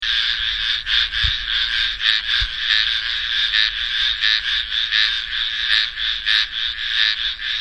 Breeding call of males is about half a dozen high pitched cheeps followed by a trill of about the same tone. There may be two to thirty or more consecutive notes.